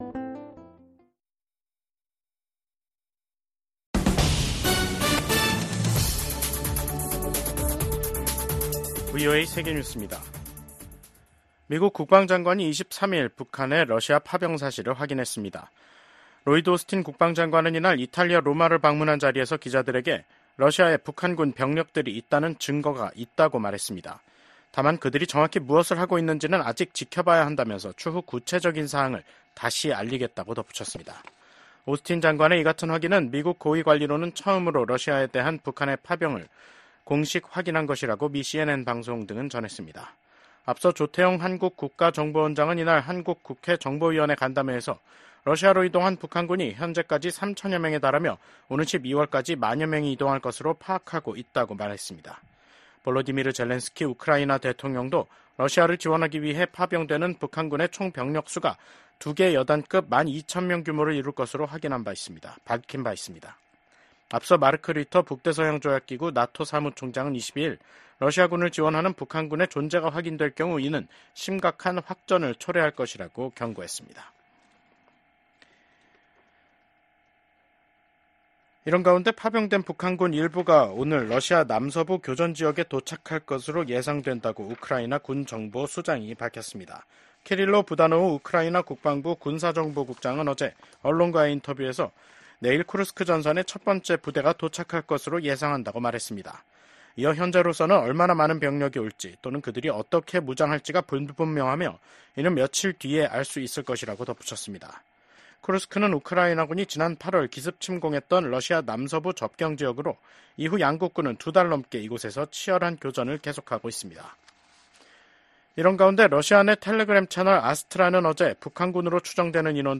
VOA 한국어 간판 뉴스 프로그램 '뉴스 투데이', 2024년 10월 23일 2부 방송입니다. 국무부는 한국이 우크라이나에 무기지원을 검토할 수 있다고 밝힌 데 대해 모든 국가의 지원을 환영한다는 입장을 밝혔습니다. 미국 하원의원들이 북한이 러시아를 지원하기 위해 특수부대를 파병했다는 보도에 깊은 우려를 표했습니다.